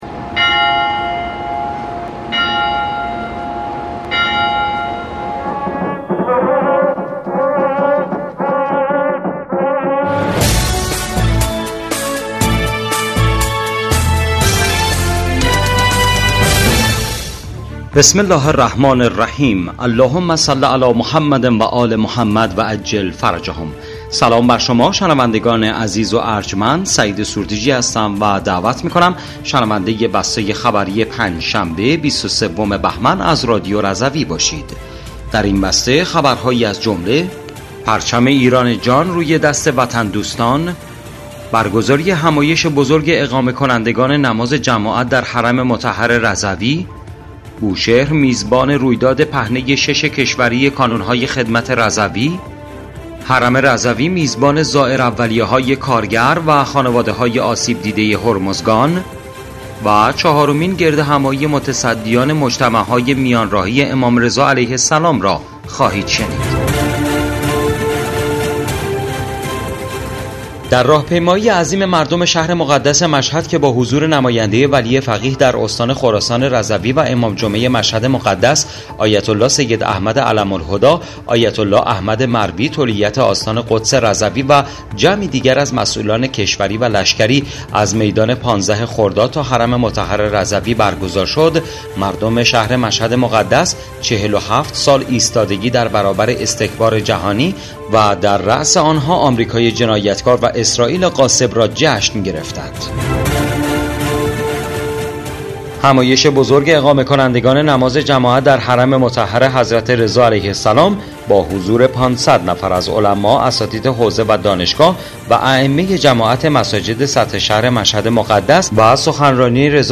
بسته خبری ۲۳ بهمن ۱۴۰۴ رادیو رضوی؛